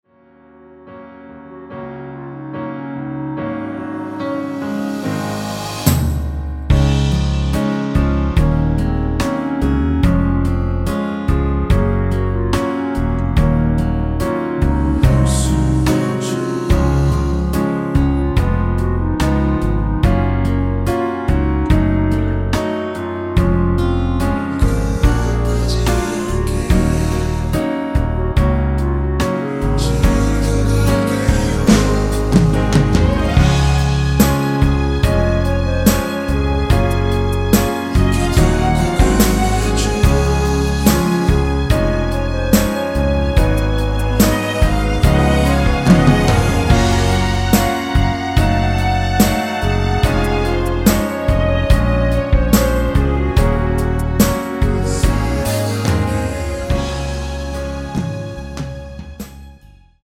원키에서(-2)내린 멜로디와 코러스 포함된 MR입니다.(미리듣기 확인)
Db
앞부분30초, 뒷부분30초씩 편집해서 올려 드리고 있습니다.
중간에 음이 끈어지고 다시 나오는 이유는